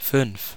Ääntäminen
Ääntäminen RP : IPA : /ˈfaɪv/ GenAm: IPA : /ˈfaɪv/ US : IPA : [faɪv] Southern US: IPA : /fäːv/ Haettu sana löytyi näillä lähdekielillä: englanti Käännös Konteksti Ääninäyte Substantiivit 1. fünf {die} 2.